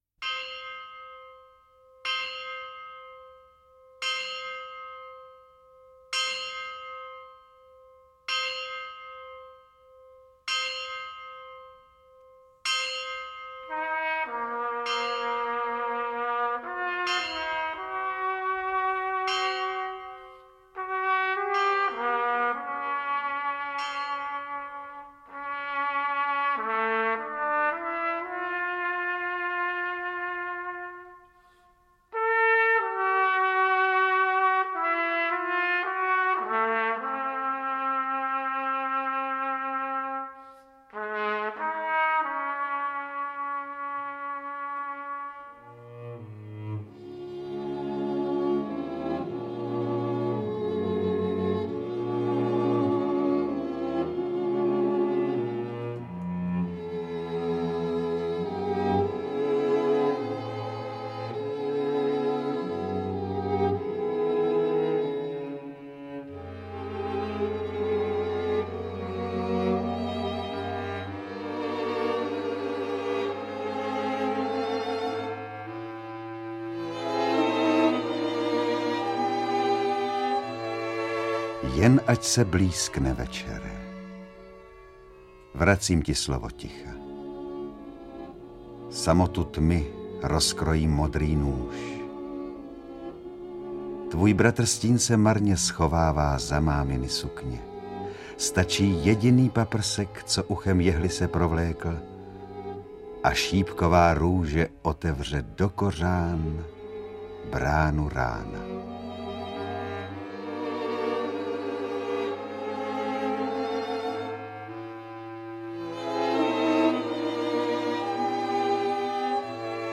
beletrie / poezie
AudioKniha ke stažení, 1 x mp3, délka 43 min., velikost 39,6 MB, česky